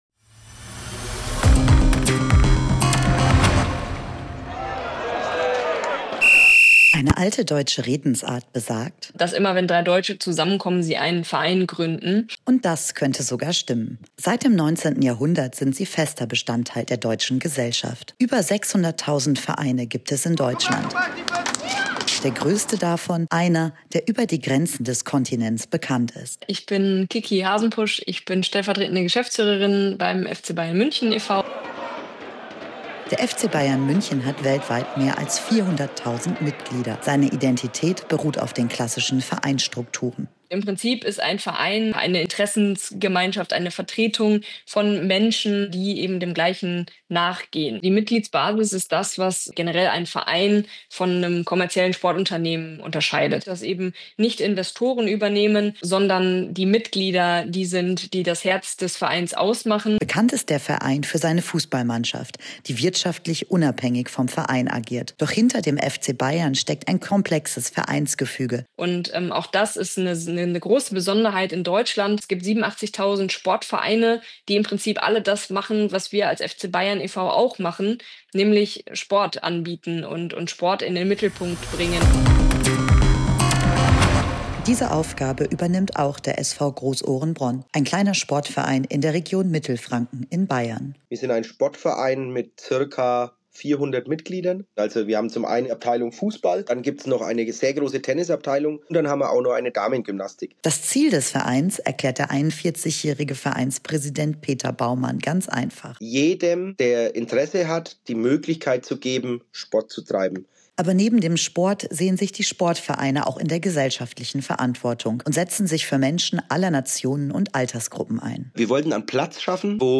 Why that is — and what they have to do with team spirit, democracy, and village culture — you can learn here in interviews with leaders and members from village clubs, girls’ football clubs, all the way to the professional club Bayern Munich.